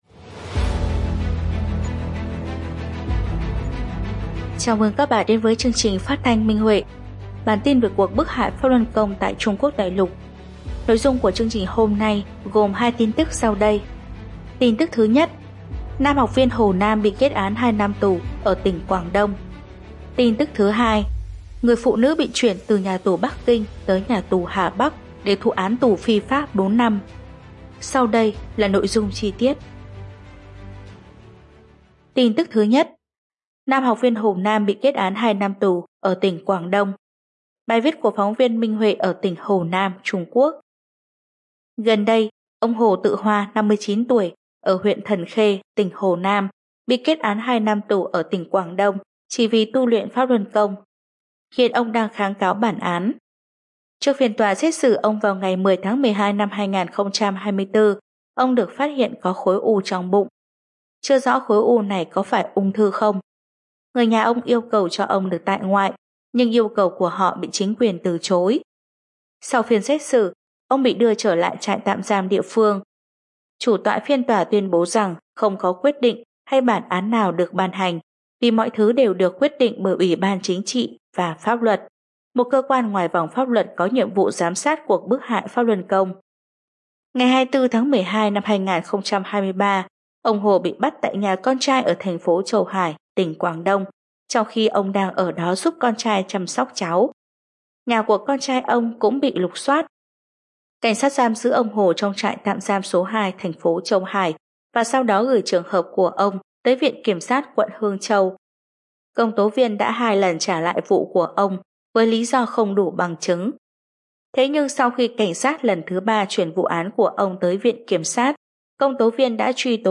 Phát thanh Minh Huệ: Tin tức về Pháp Luân Đại Pháp tại Đại Lục – Ngày 16/01/2025 - Minh Huệ Net